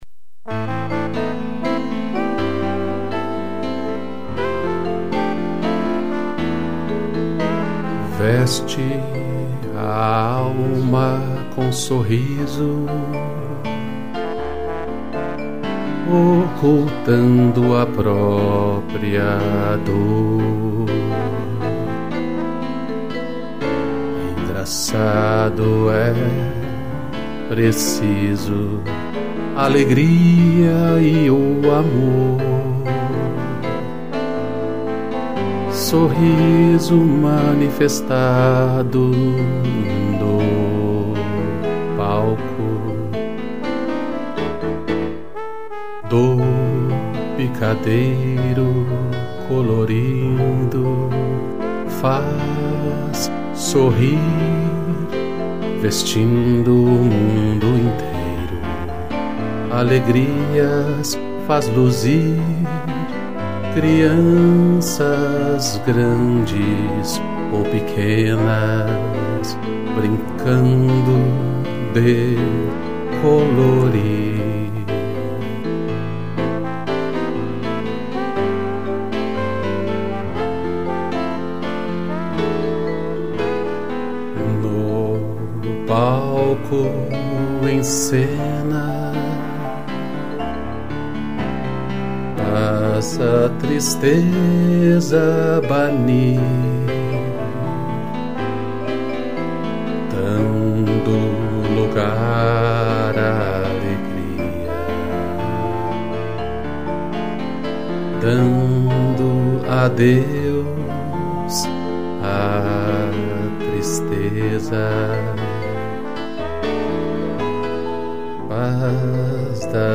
Voz
2 pianos e trombone